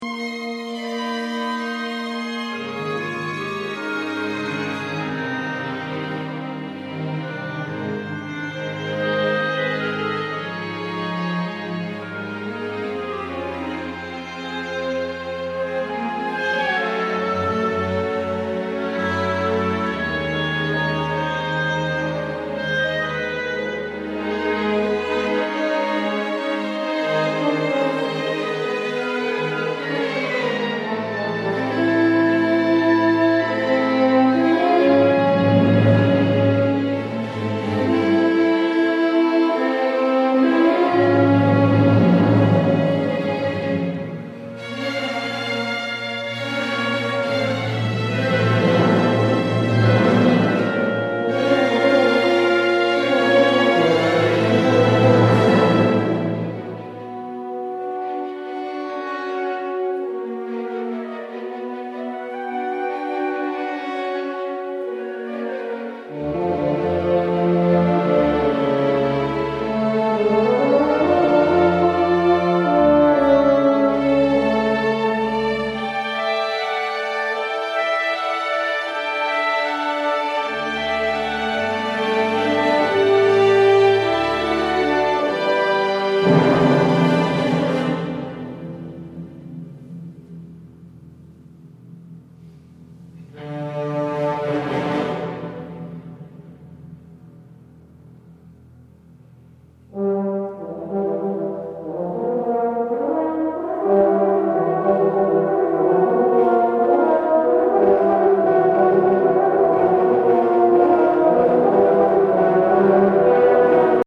Concerto for Euphonium and Loopstation. Recorded live at Christ Church, Skipton on 8 November 2014